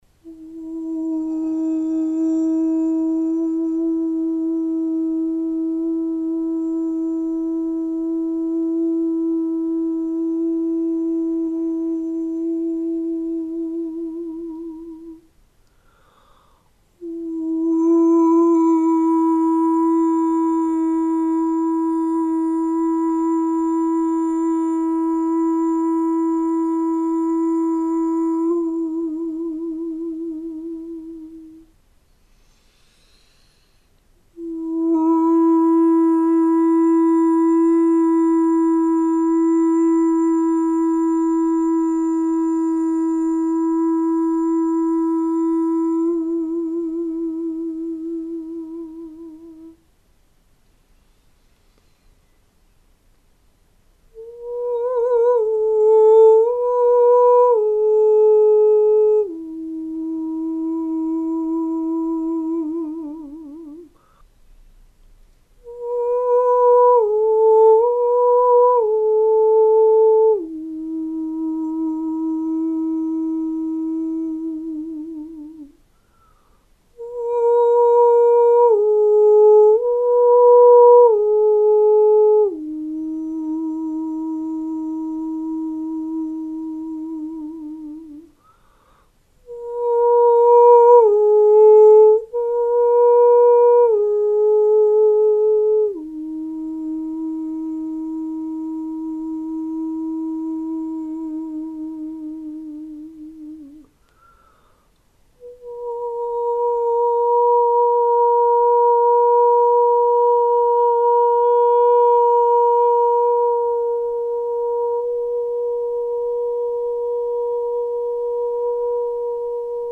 Prosperity+Activation+Sounds.mp3